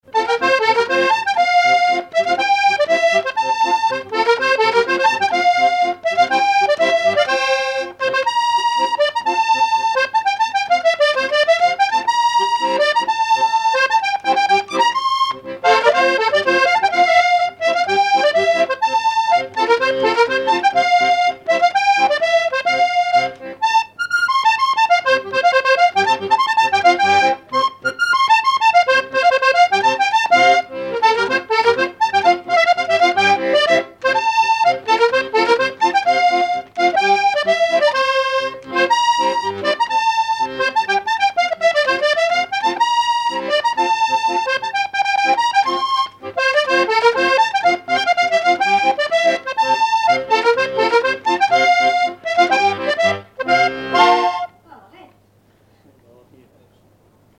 Enregistrement original de l'édition sur disque vinyle
musique pour les assauts de danse et le bal.
accordéon(s), accordéoniste ; musique traditionnelle
danse : quadrille